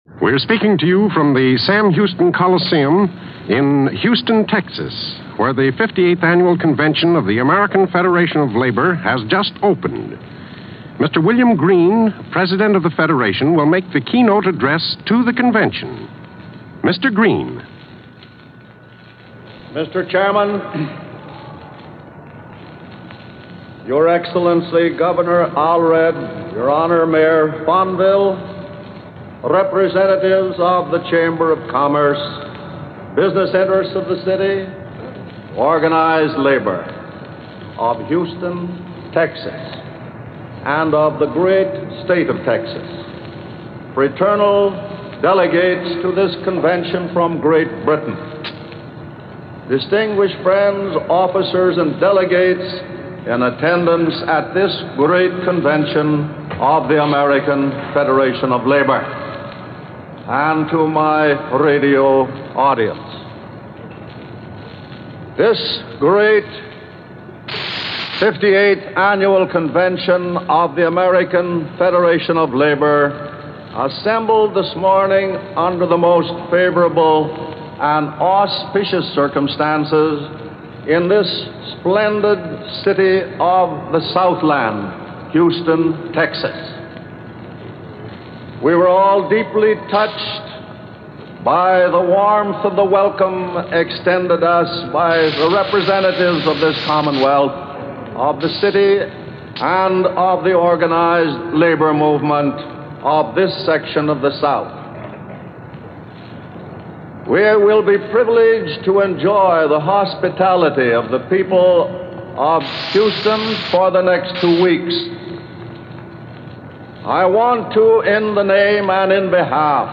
Click on the link here for audio Player – William Green – Address to 58th Convention of AF of L – October 3, 1938 – Gordon Skene Sound Collection
AF of L President William Green lays it out in an opening day address to the 58th annual convention in Houston, Texas .